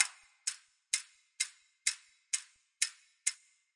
everyday sounds » clock ticking no effect
描述：clock ticking
标签： time ticking clock wallclock tictac
声道立体声